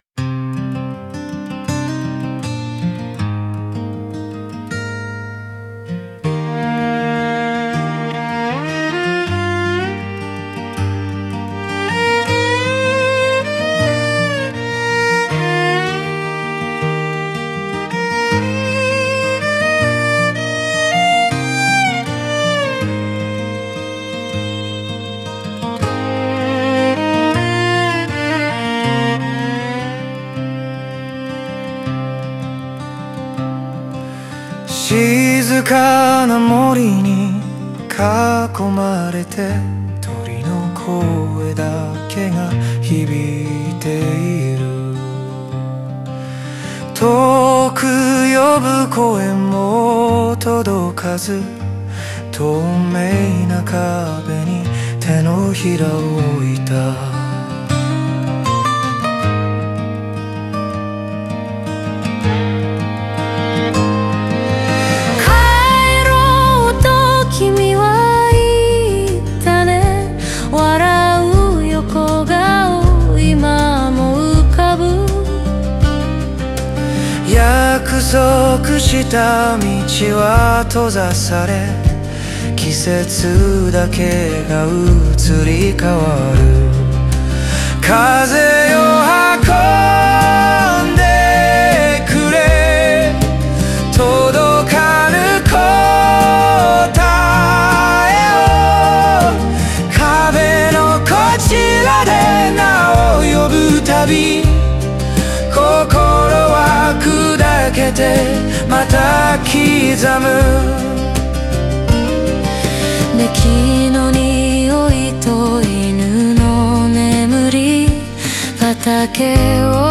オリジナル曲♪
歌全体は、孤独を超えてなお記憶に寄り添う人間の強さと儚さを、静かなフォーク調の旋律にのせて表現したものです。